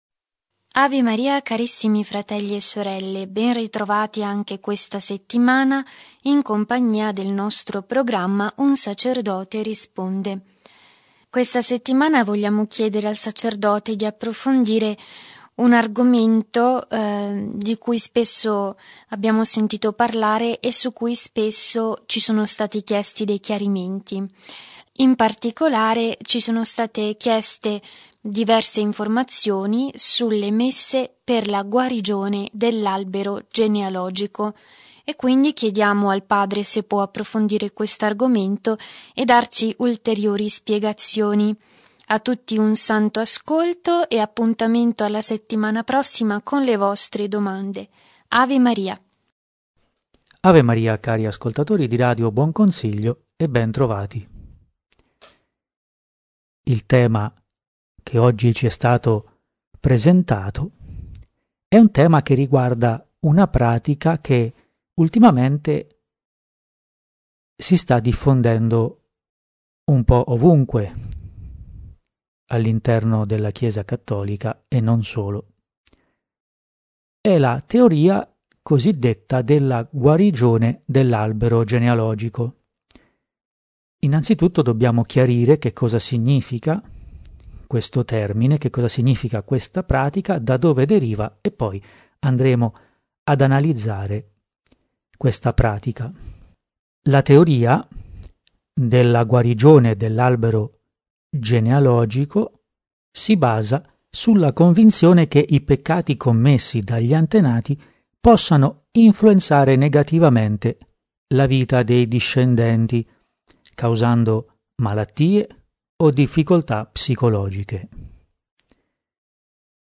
Genere: Un sacerdote risponde.